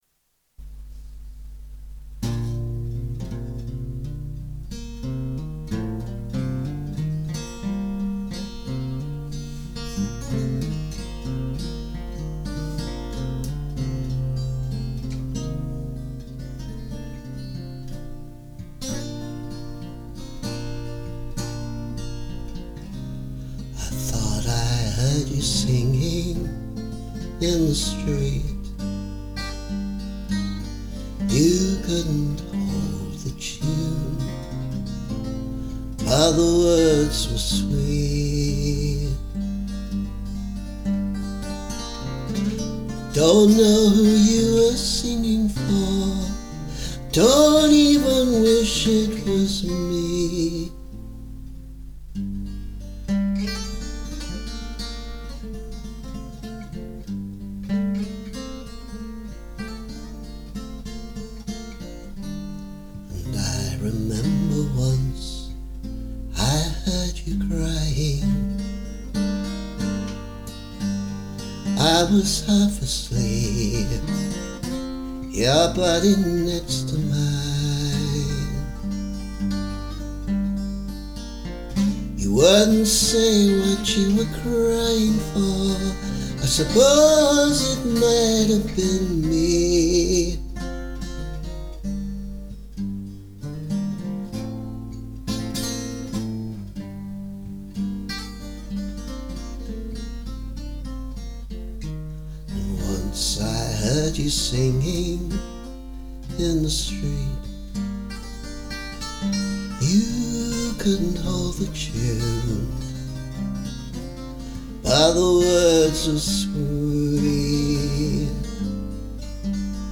And a version with a second guitar, just as a tryout. I think the final version will be quite different (and will need some work on the vocals: I wasn’t getting those low notes very well today).